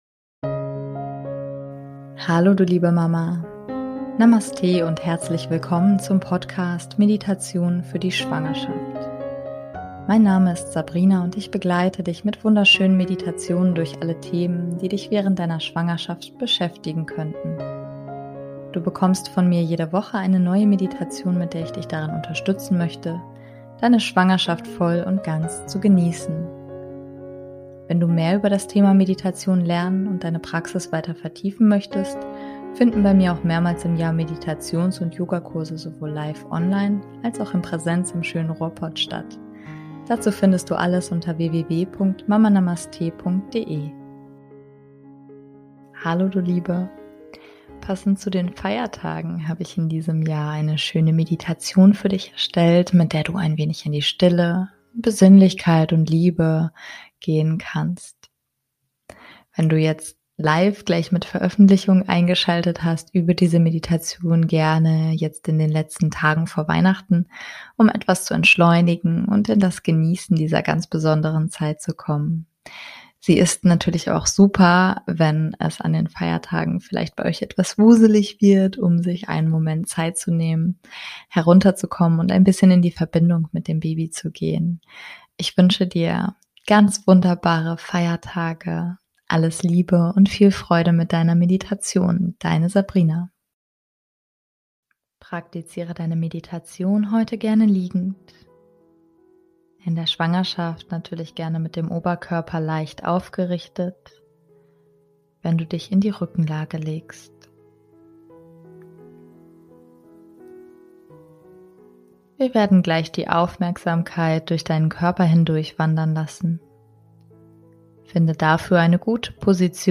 #067 - Meditation - Liebe und Frieden für die Feiertage ~ Meditationen für die Schwangerschaft und Geburt - mama.namaste Podcast